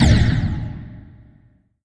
explode.mp3